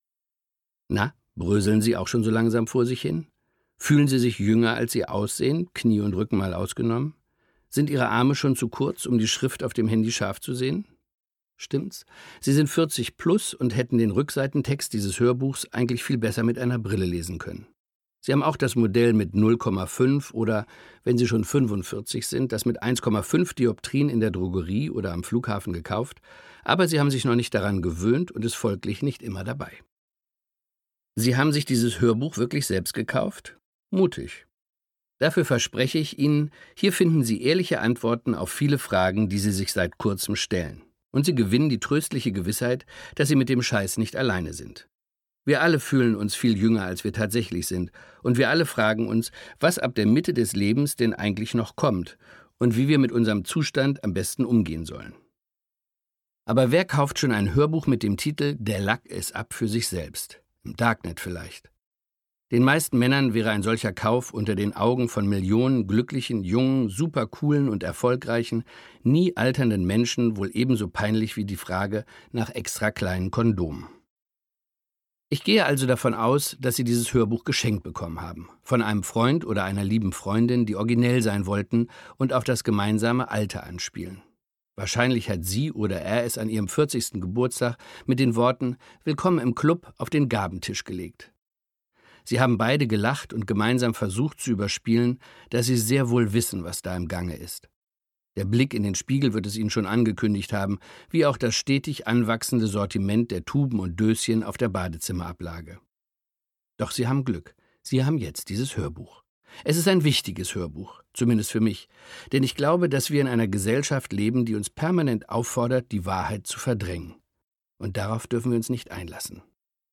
Der Lack ist ab War's das schon oder kommt noch was? Kai Wiesinger (Autor) Kai Wiesinger , Bettina Zimmermann (Sprecher) Audio-CD 2019 | 2.